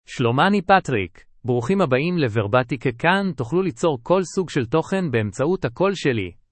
MaleHebrew (Israel)
PatrickMale Hebrew AI voice
Patrick is a male AI voice for Hebrew (Israel).
Voice sample
Listen to Patrick's male Hebrew voice.
Patrick delivers clear pronunciation with authentic Israel Hebrew intonation, making your content sound professionally produced.